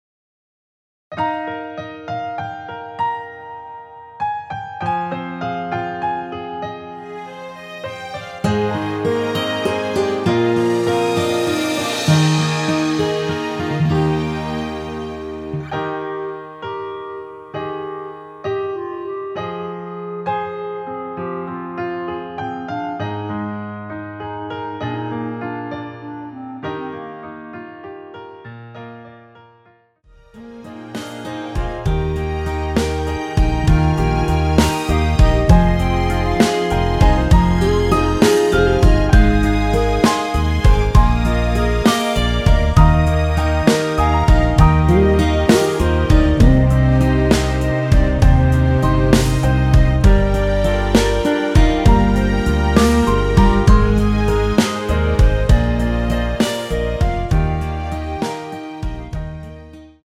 Eb
노래방에서 노래를 부르실때 노래 부분에 가이드 멜로디가 따라 나와서
멜로디 MR이라고 합니다.
앞부분30초, 뒷부분30초씩 편집해서 올려 드리고 있습니다.
중간에 음이 끈어지고 다시 나오는 이유는